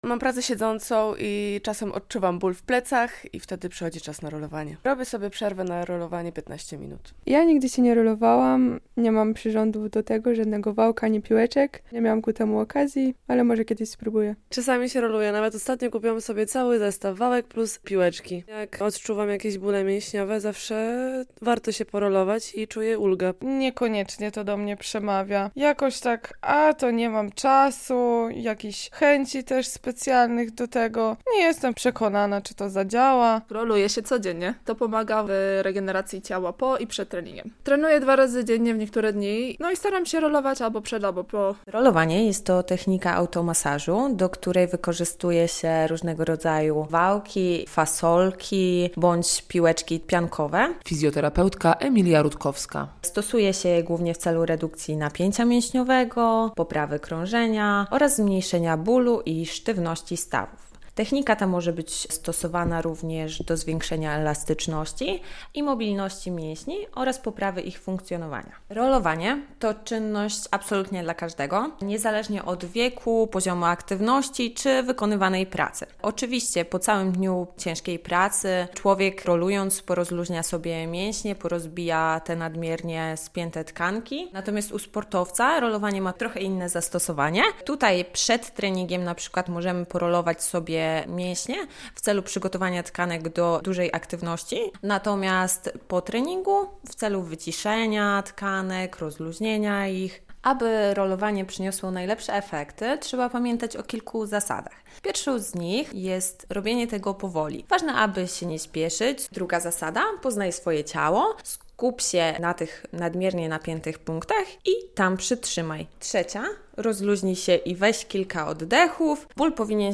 Rolowanie mięśni - relacja